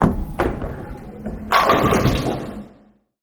strike.ogg